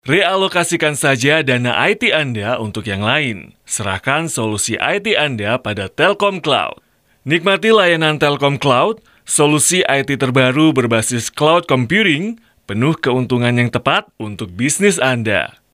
Indonesian Voice Over Talent
Sprechprobe: Werbung (Muttersprache):